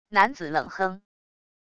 男子冷哼wav音频